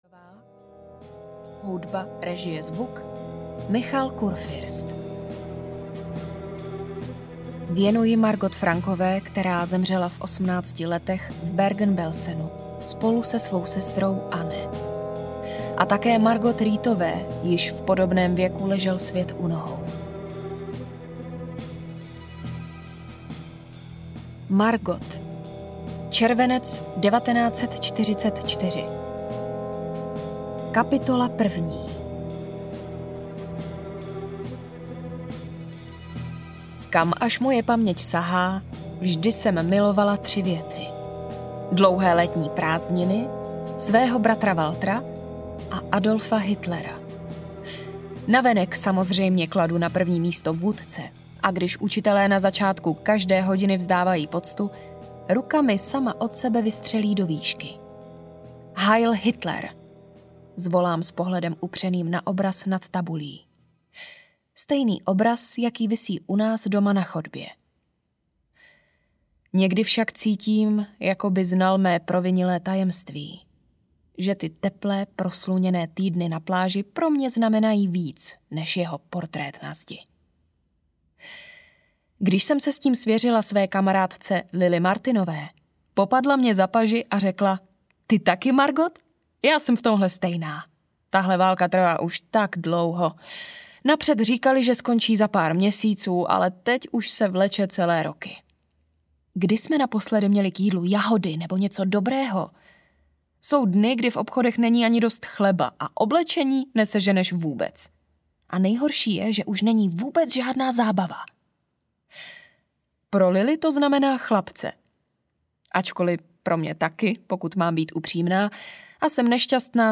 Dopisy ze Sachsenhausenu audiokniha
Ukázka z knihy